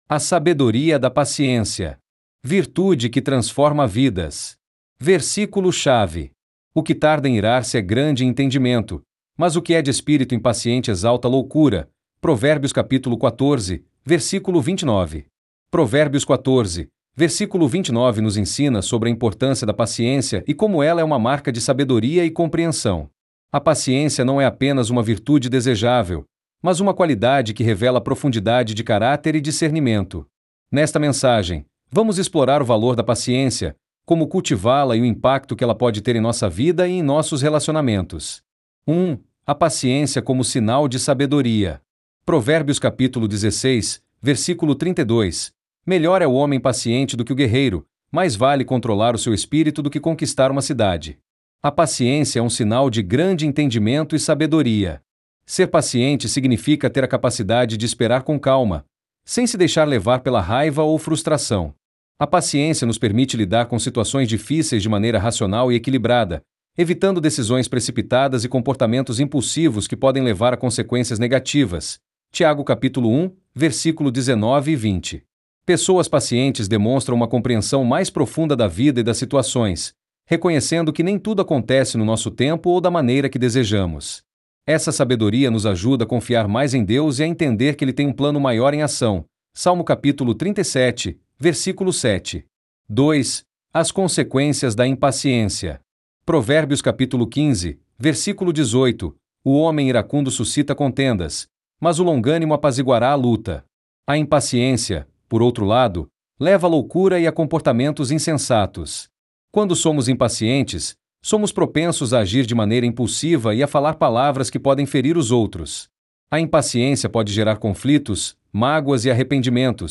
DEVOCIONAL